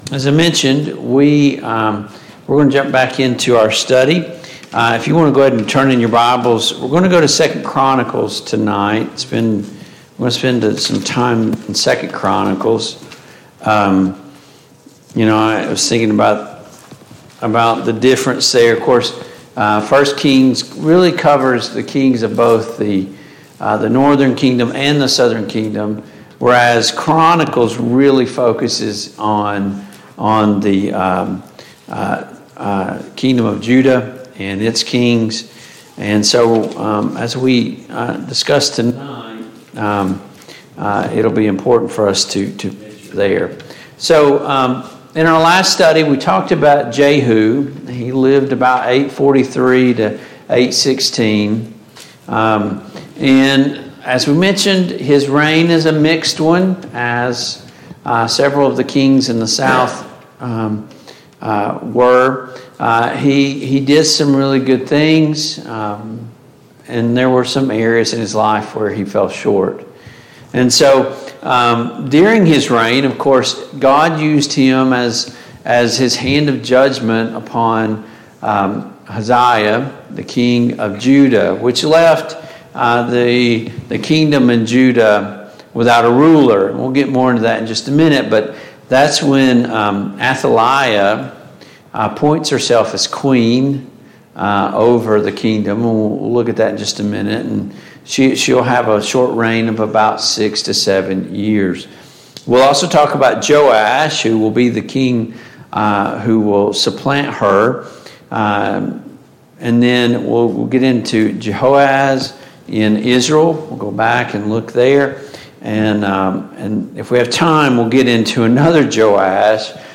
Passage: 2 Chronicles 22, 2 Kings 11, 2 Chronicles 23, 2 Chronicles 24, 2 Kings 12 Service Type: Mid-Week Bible Study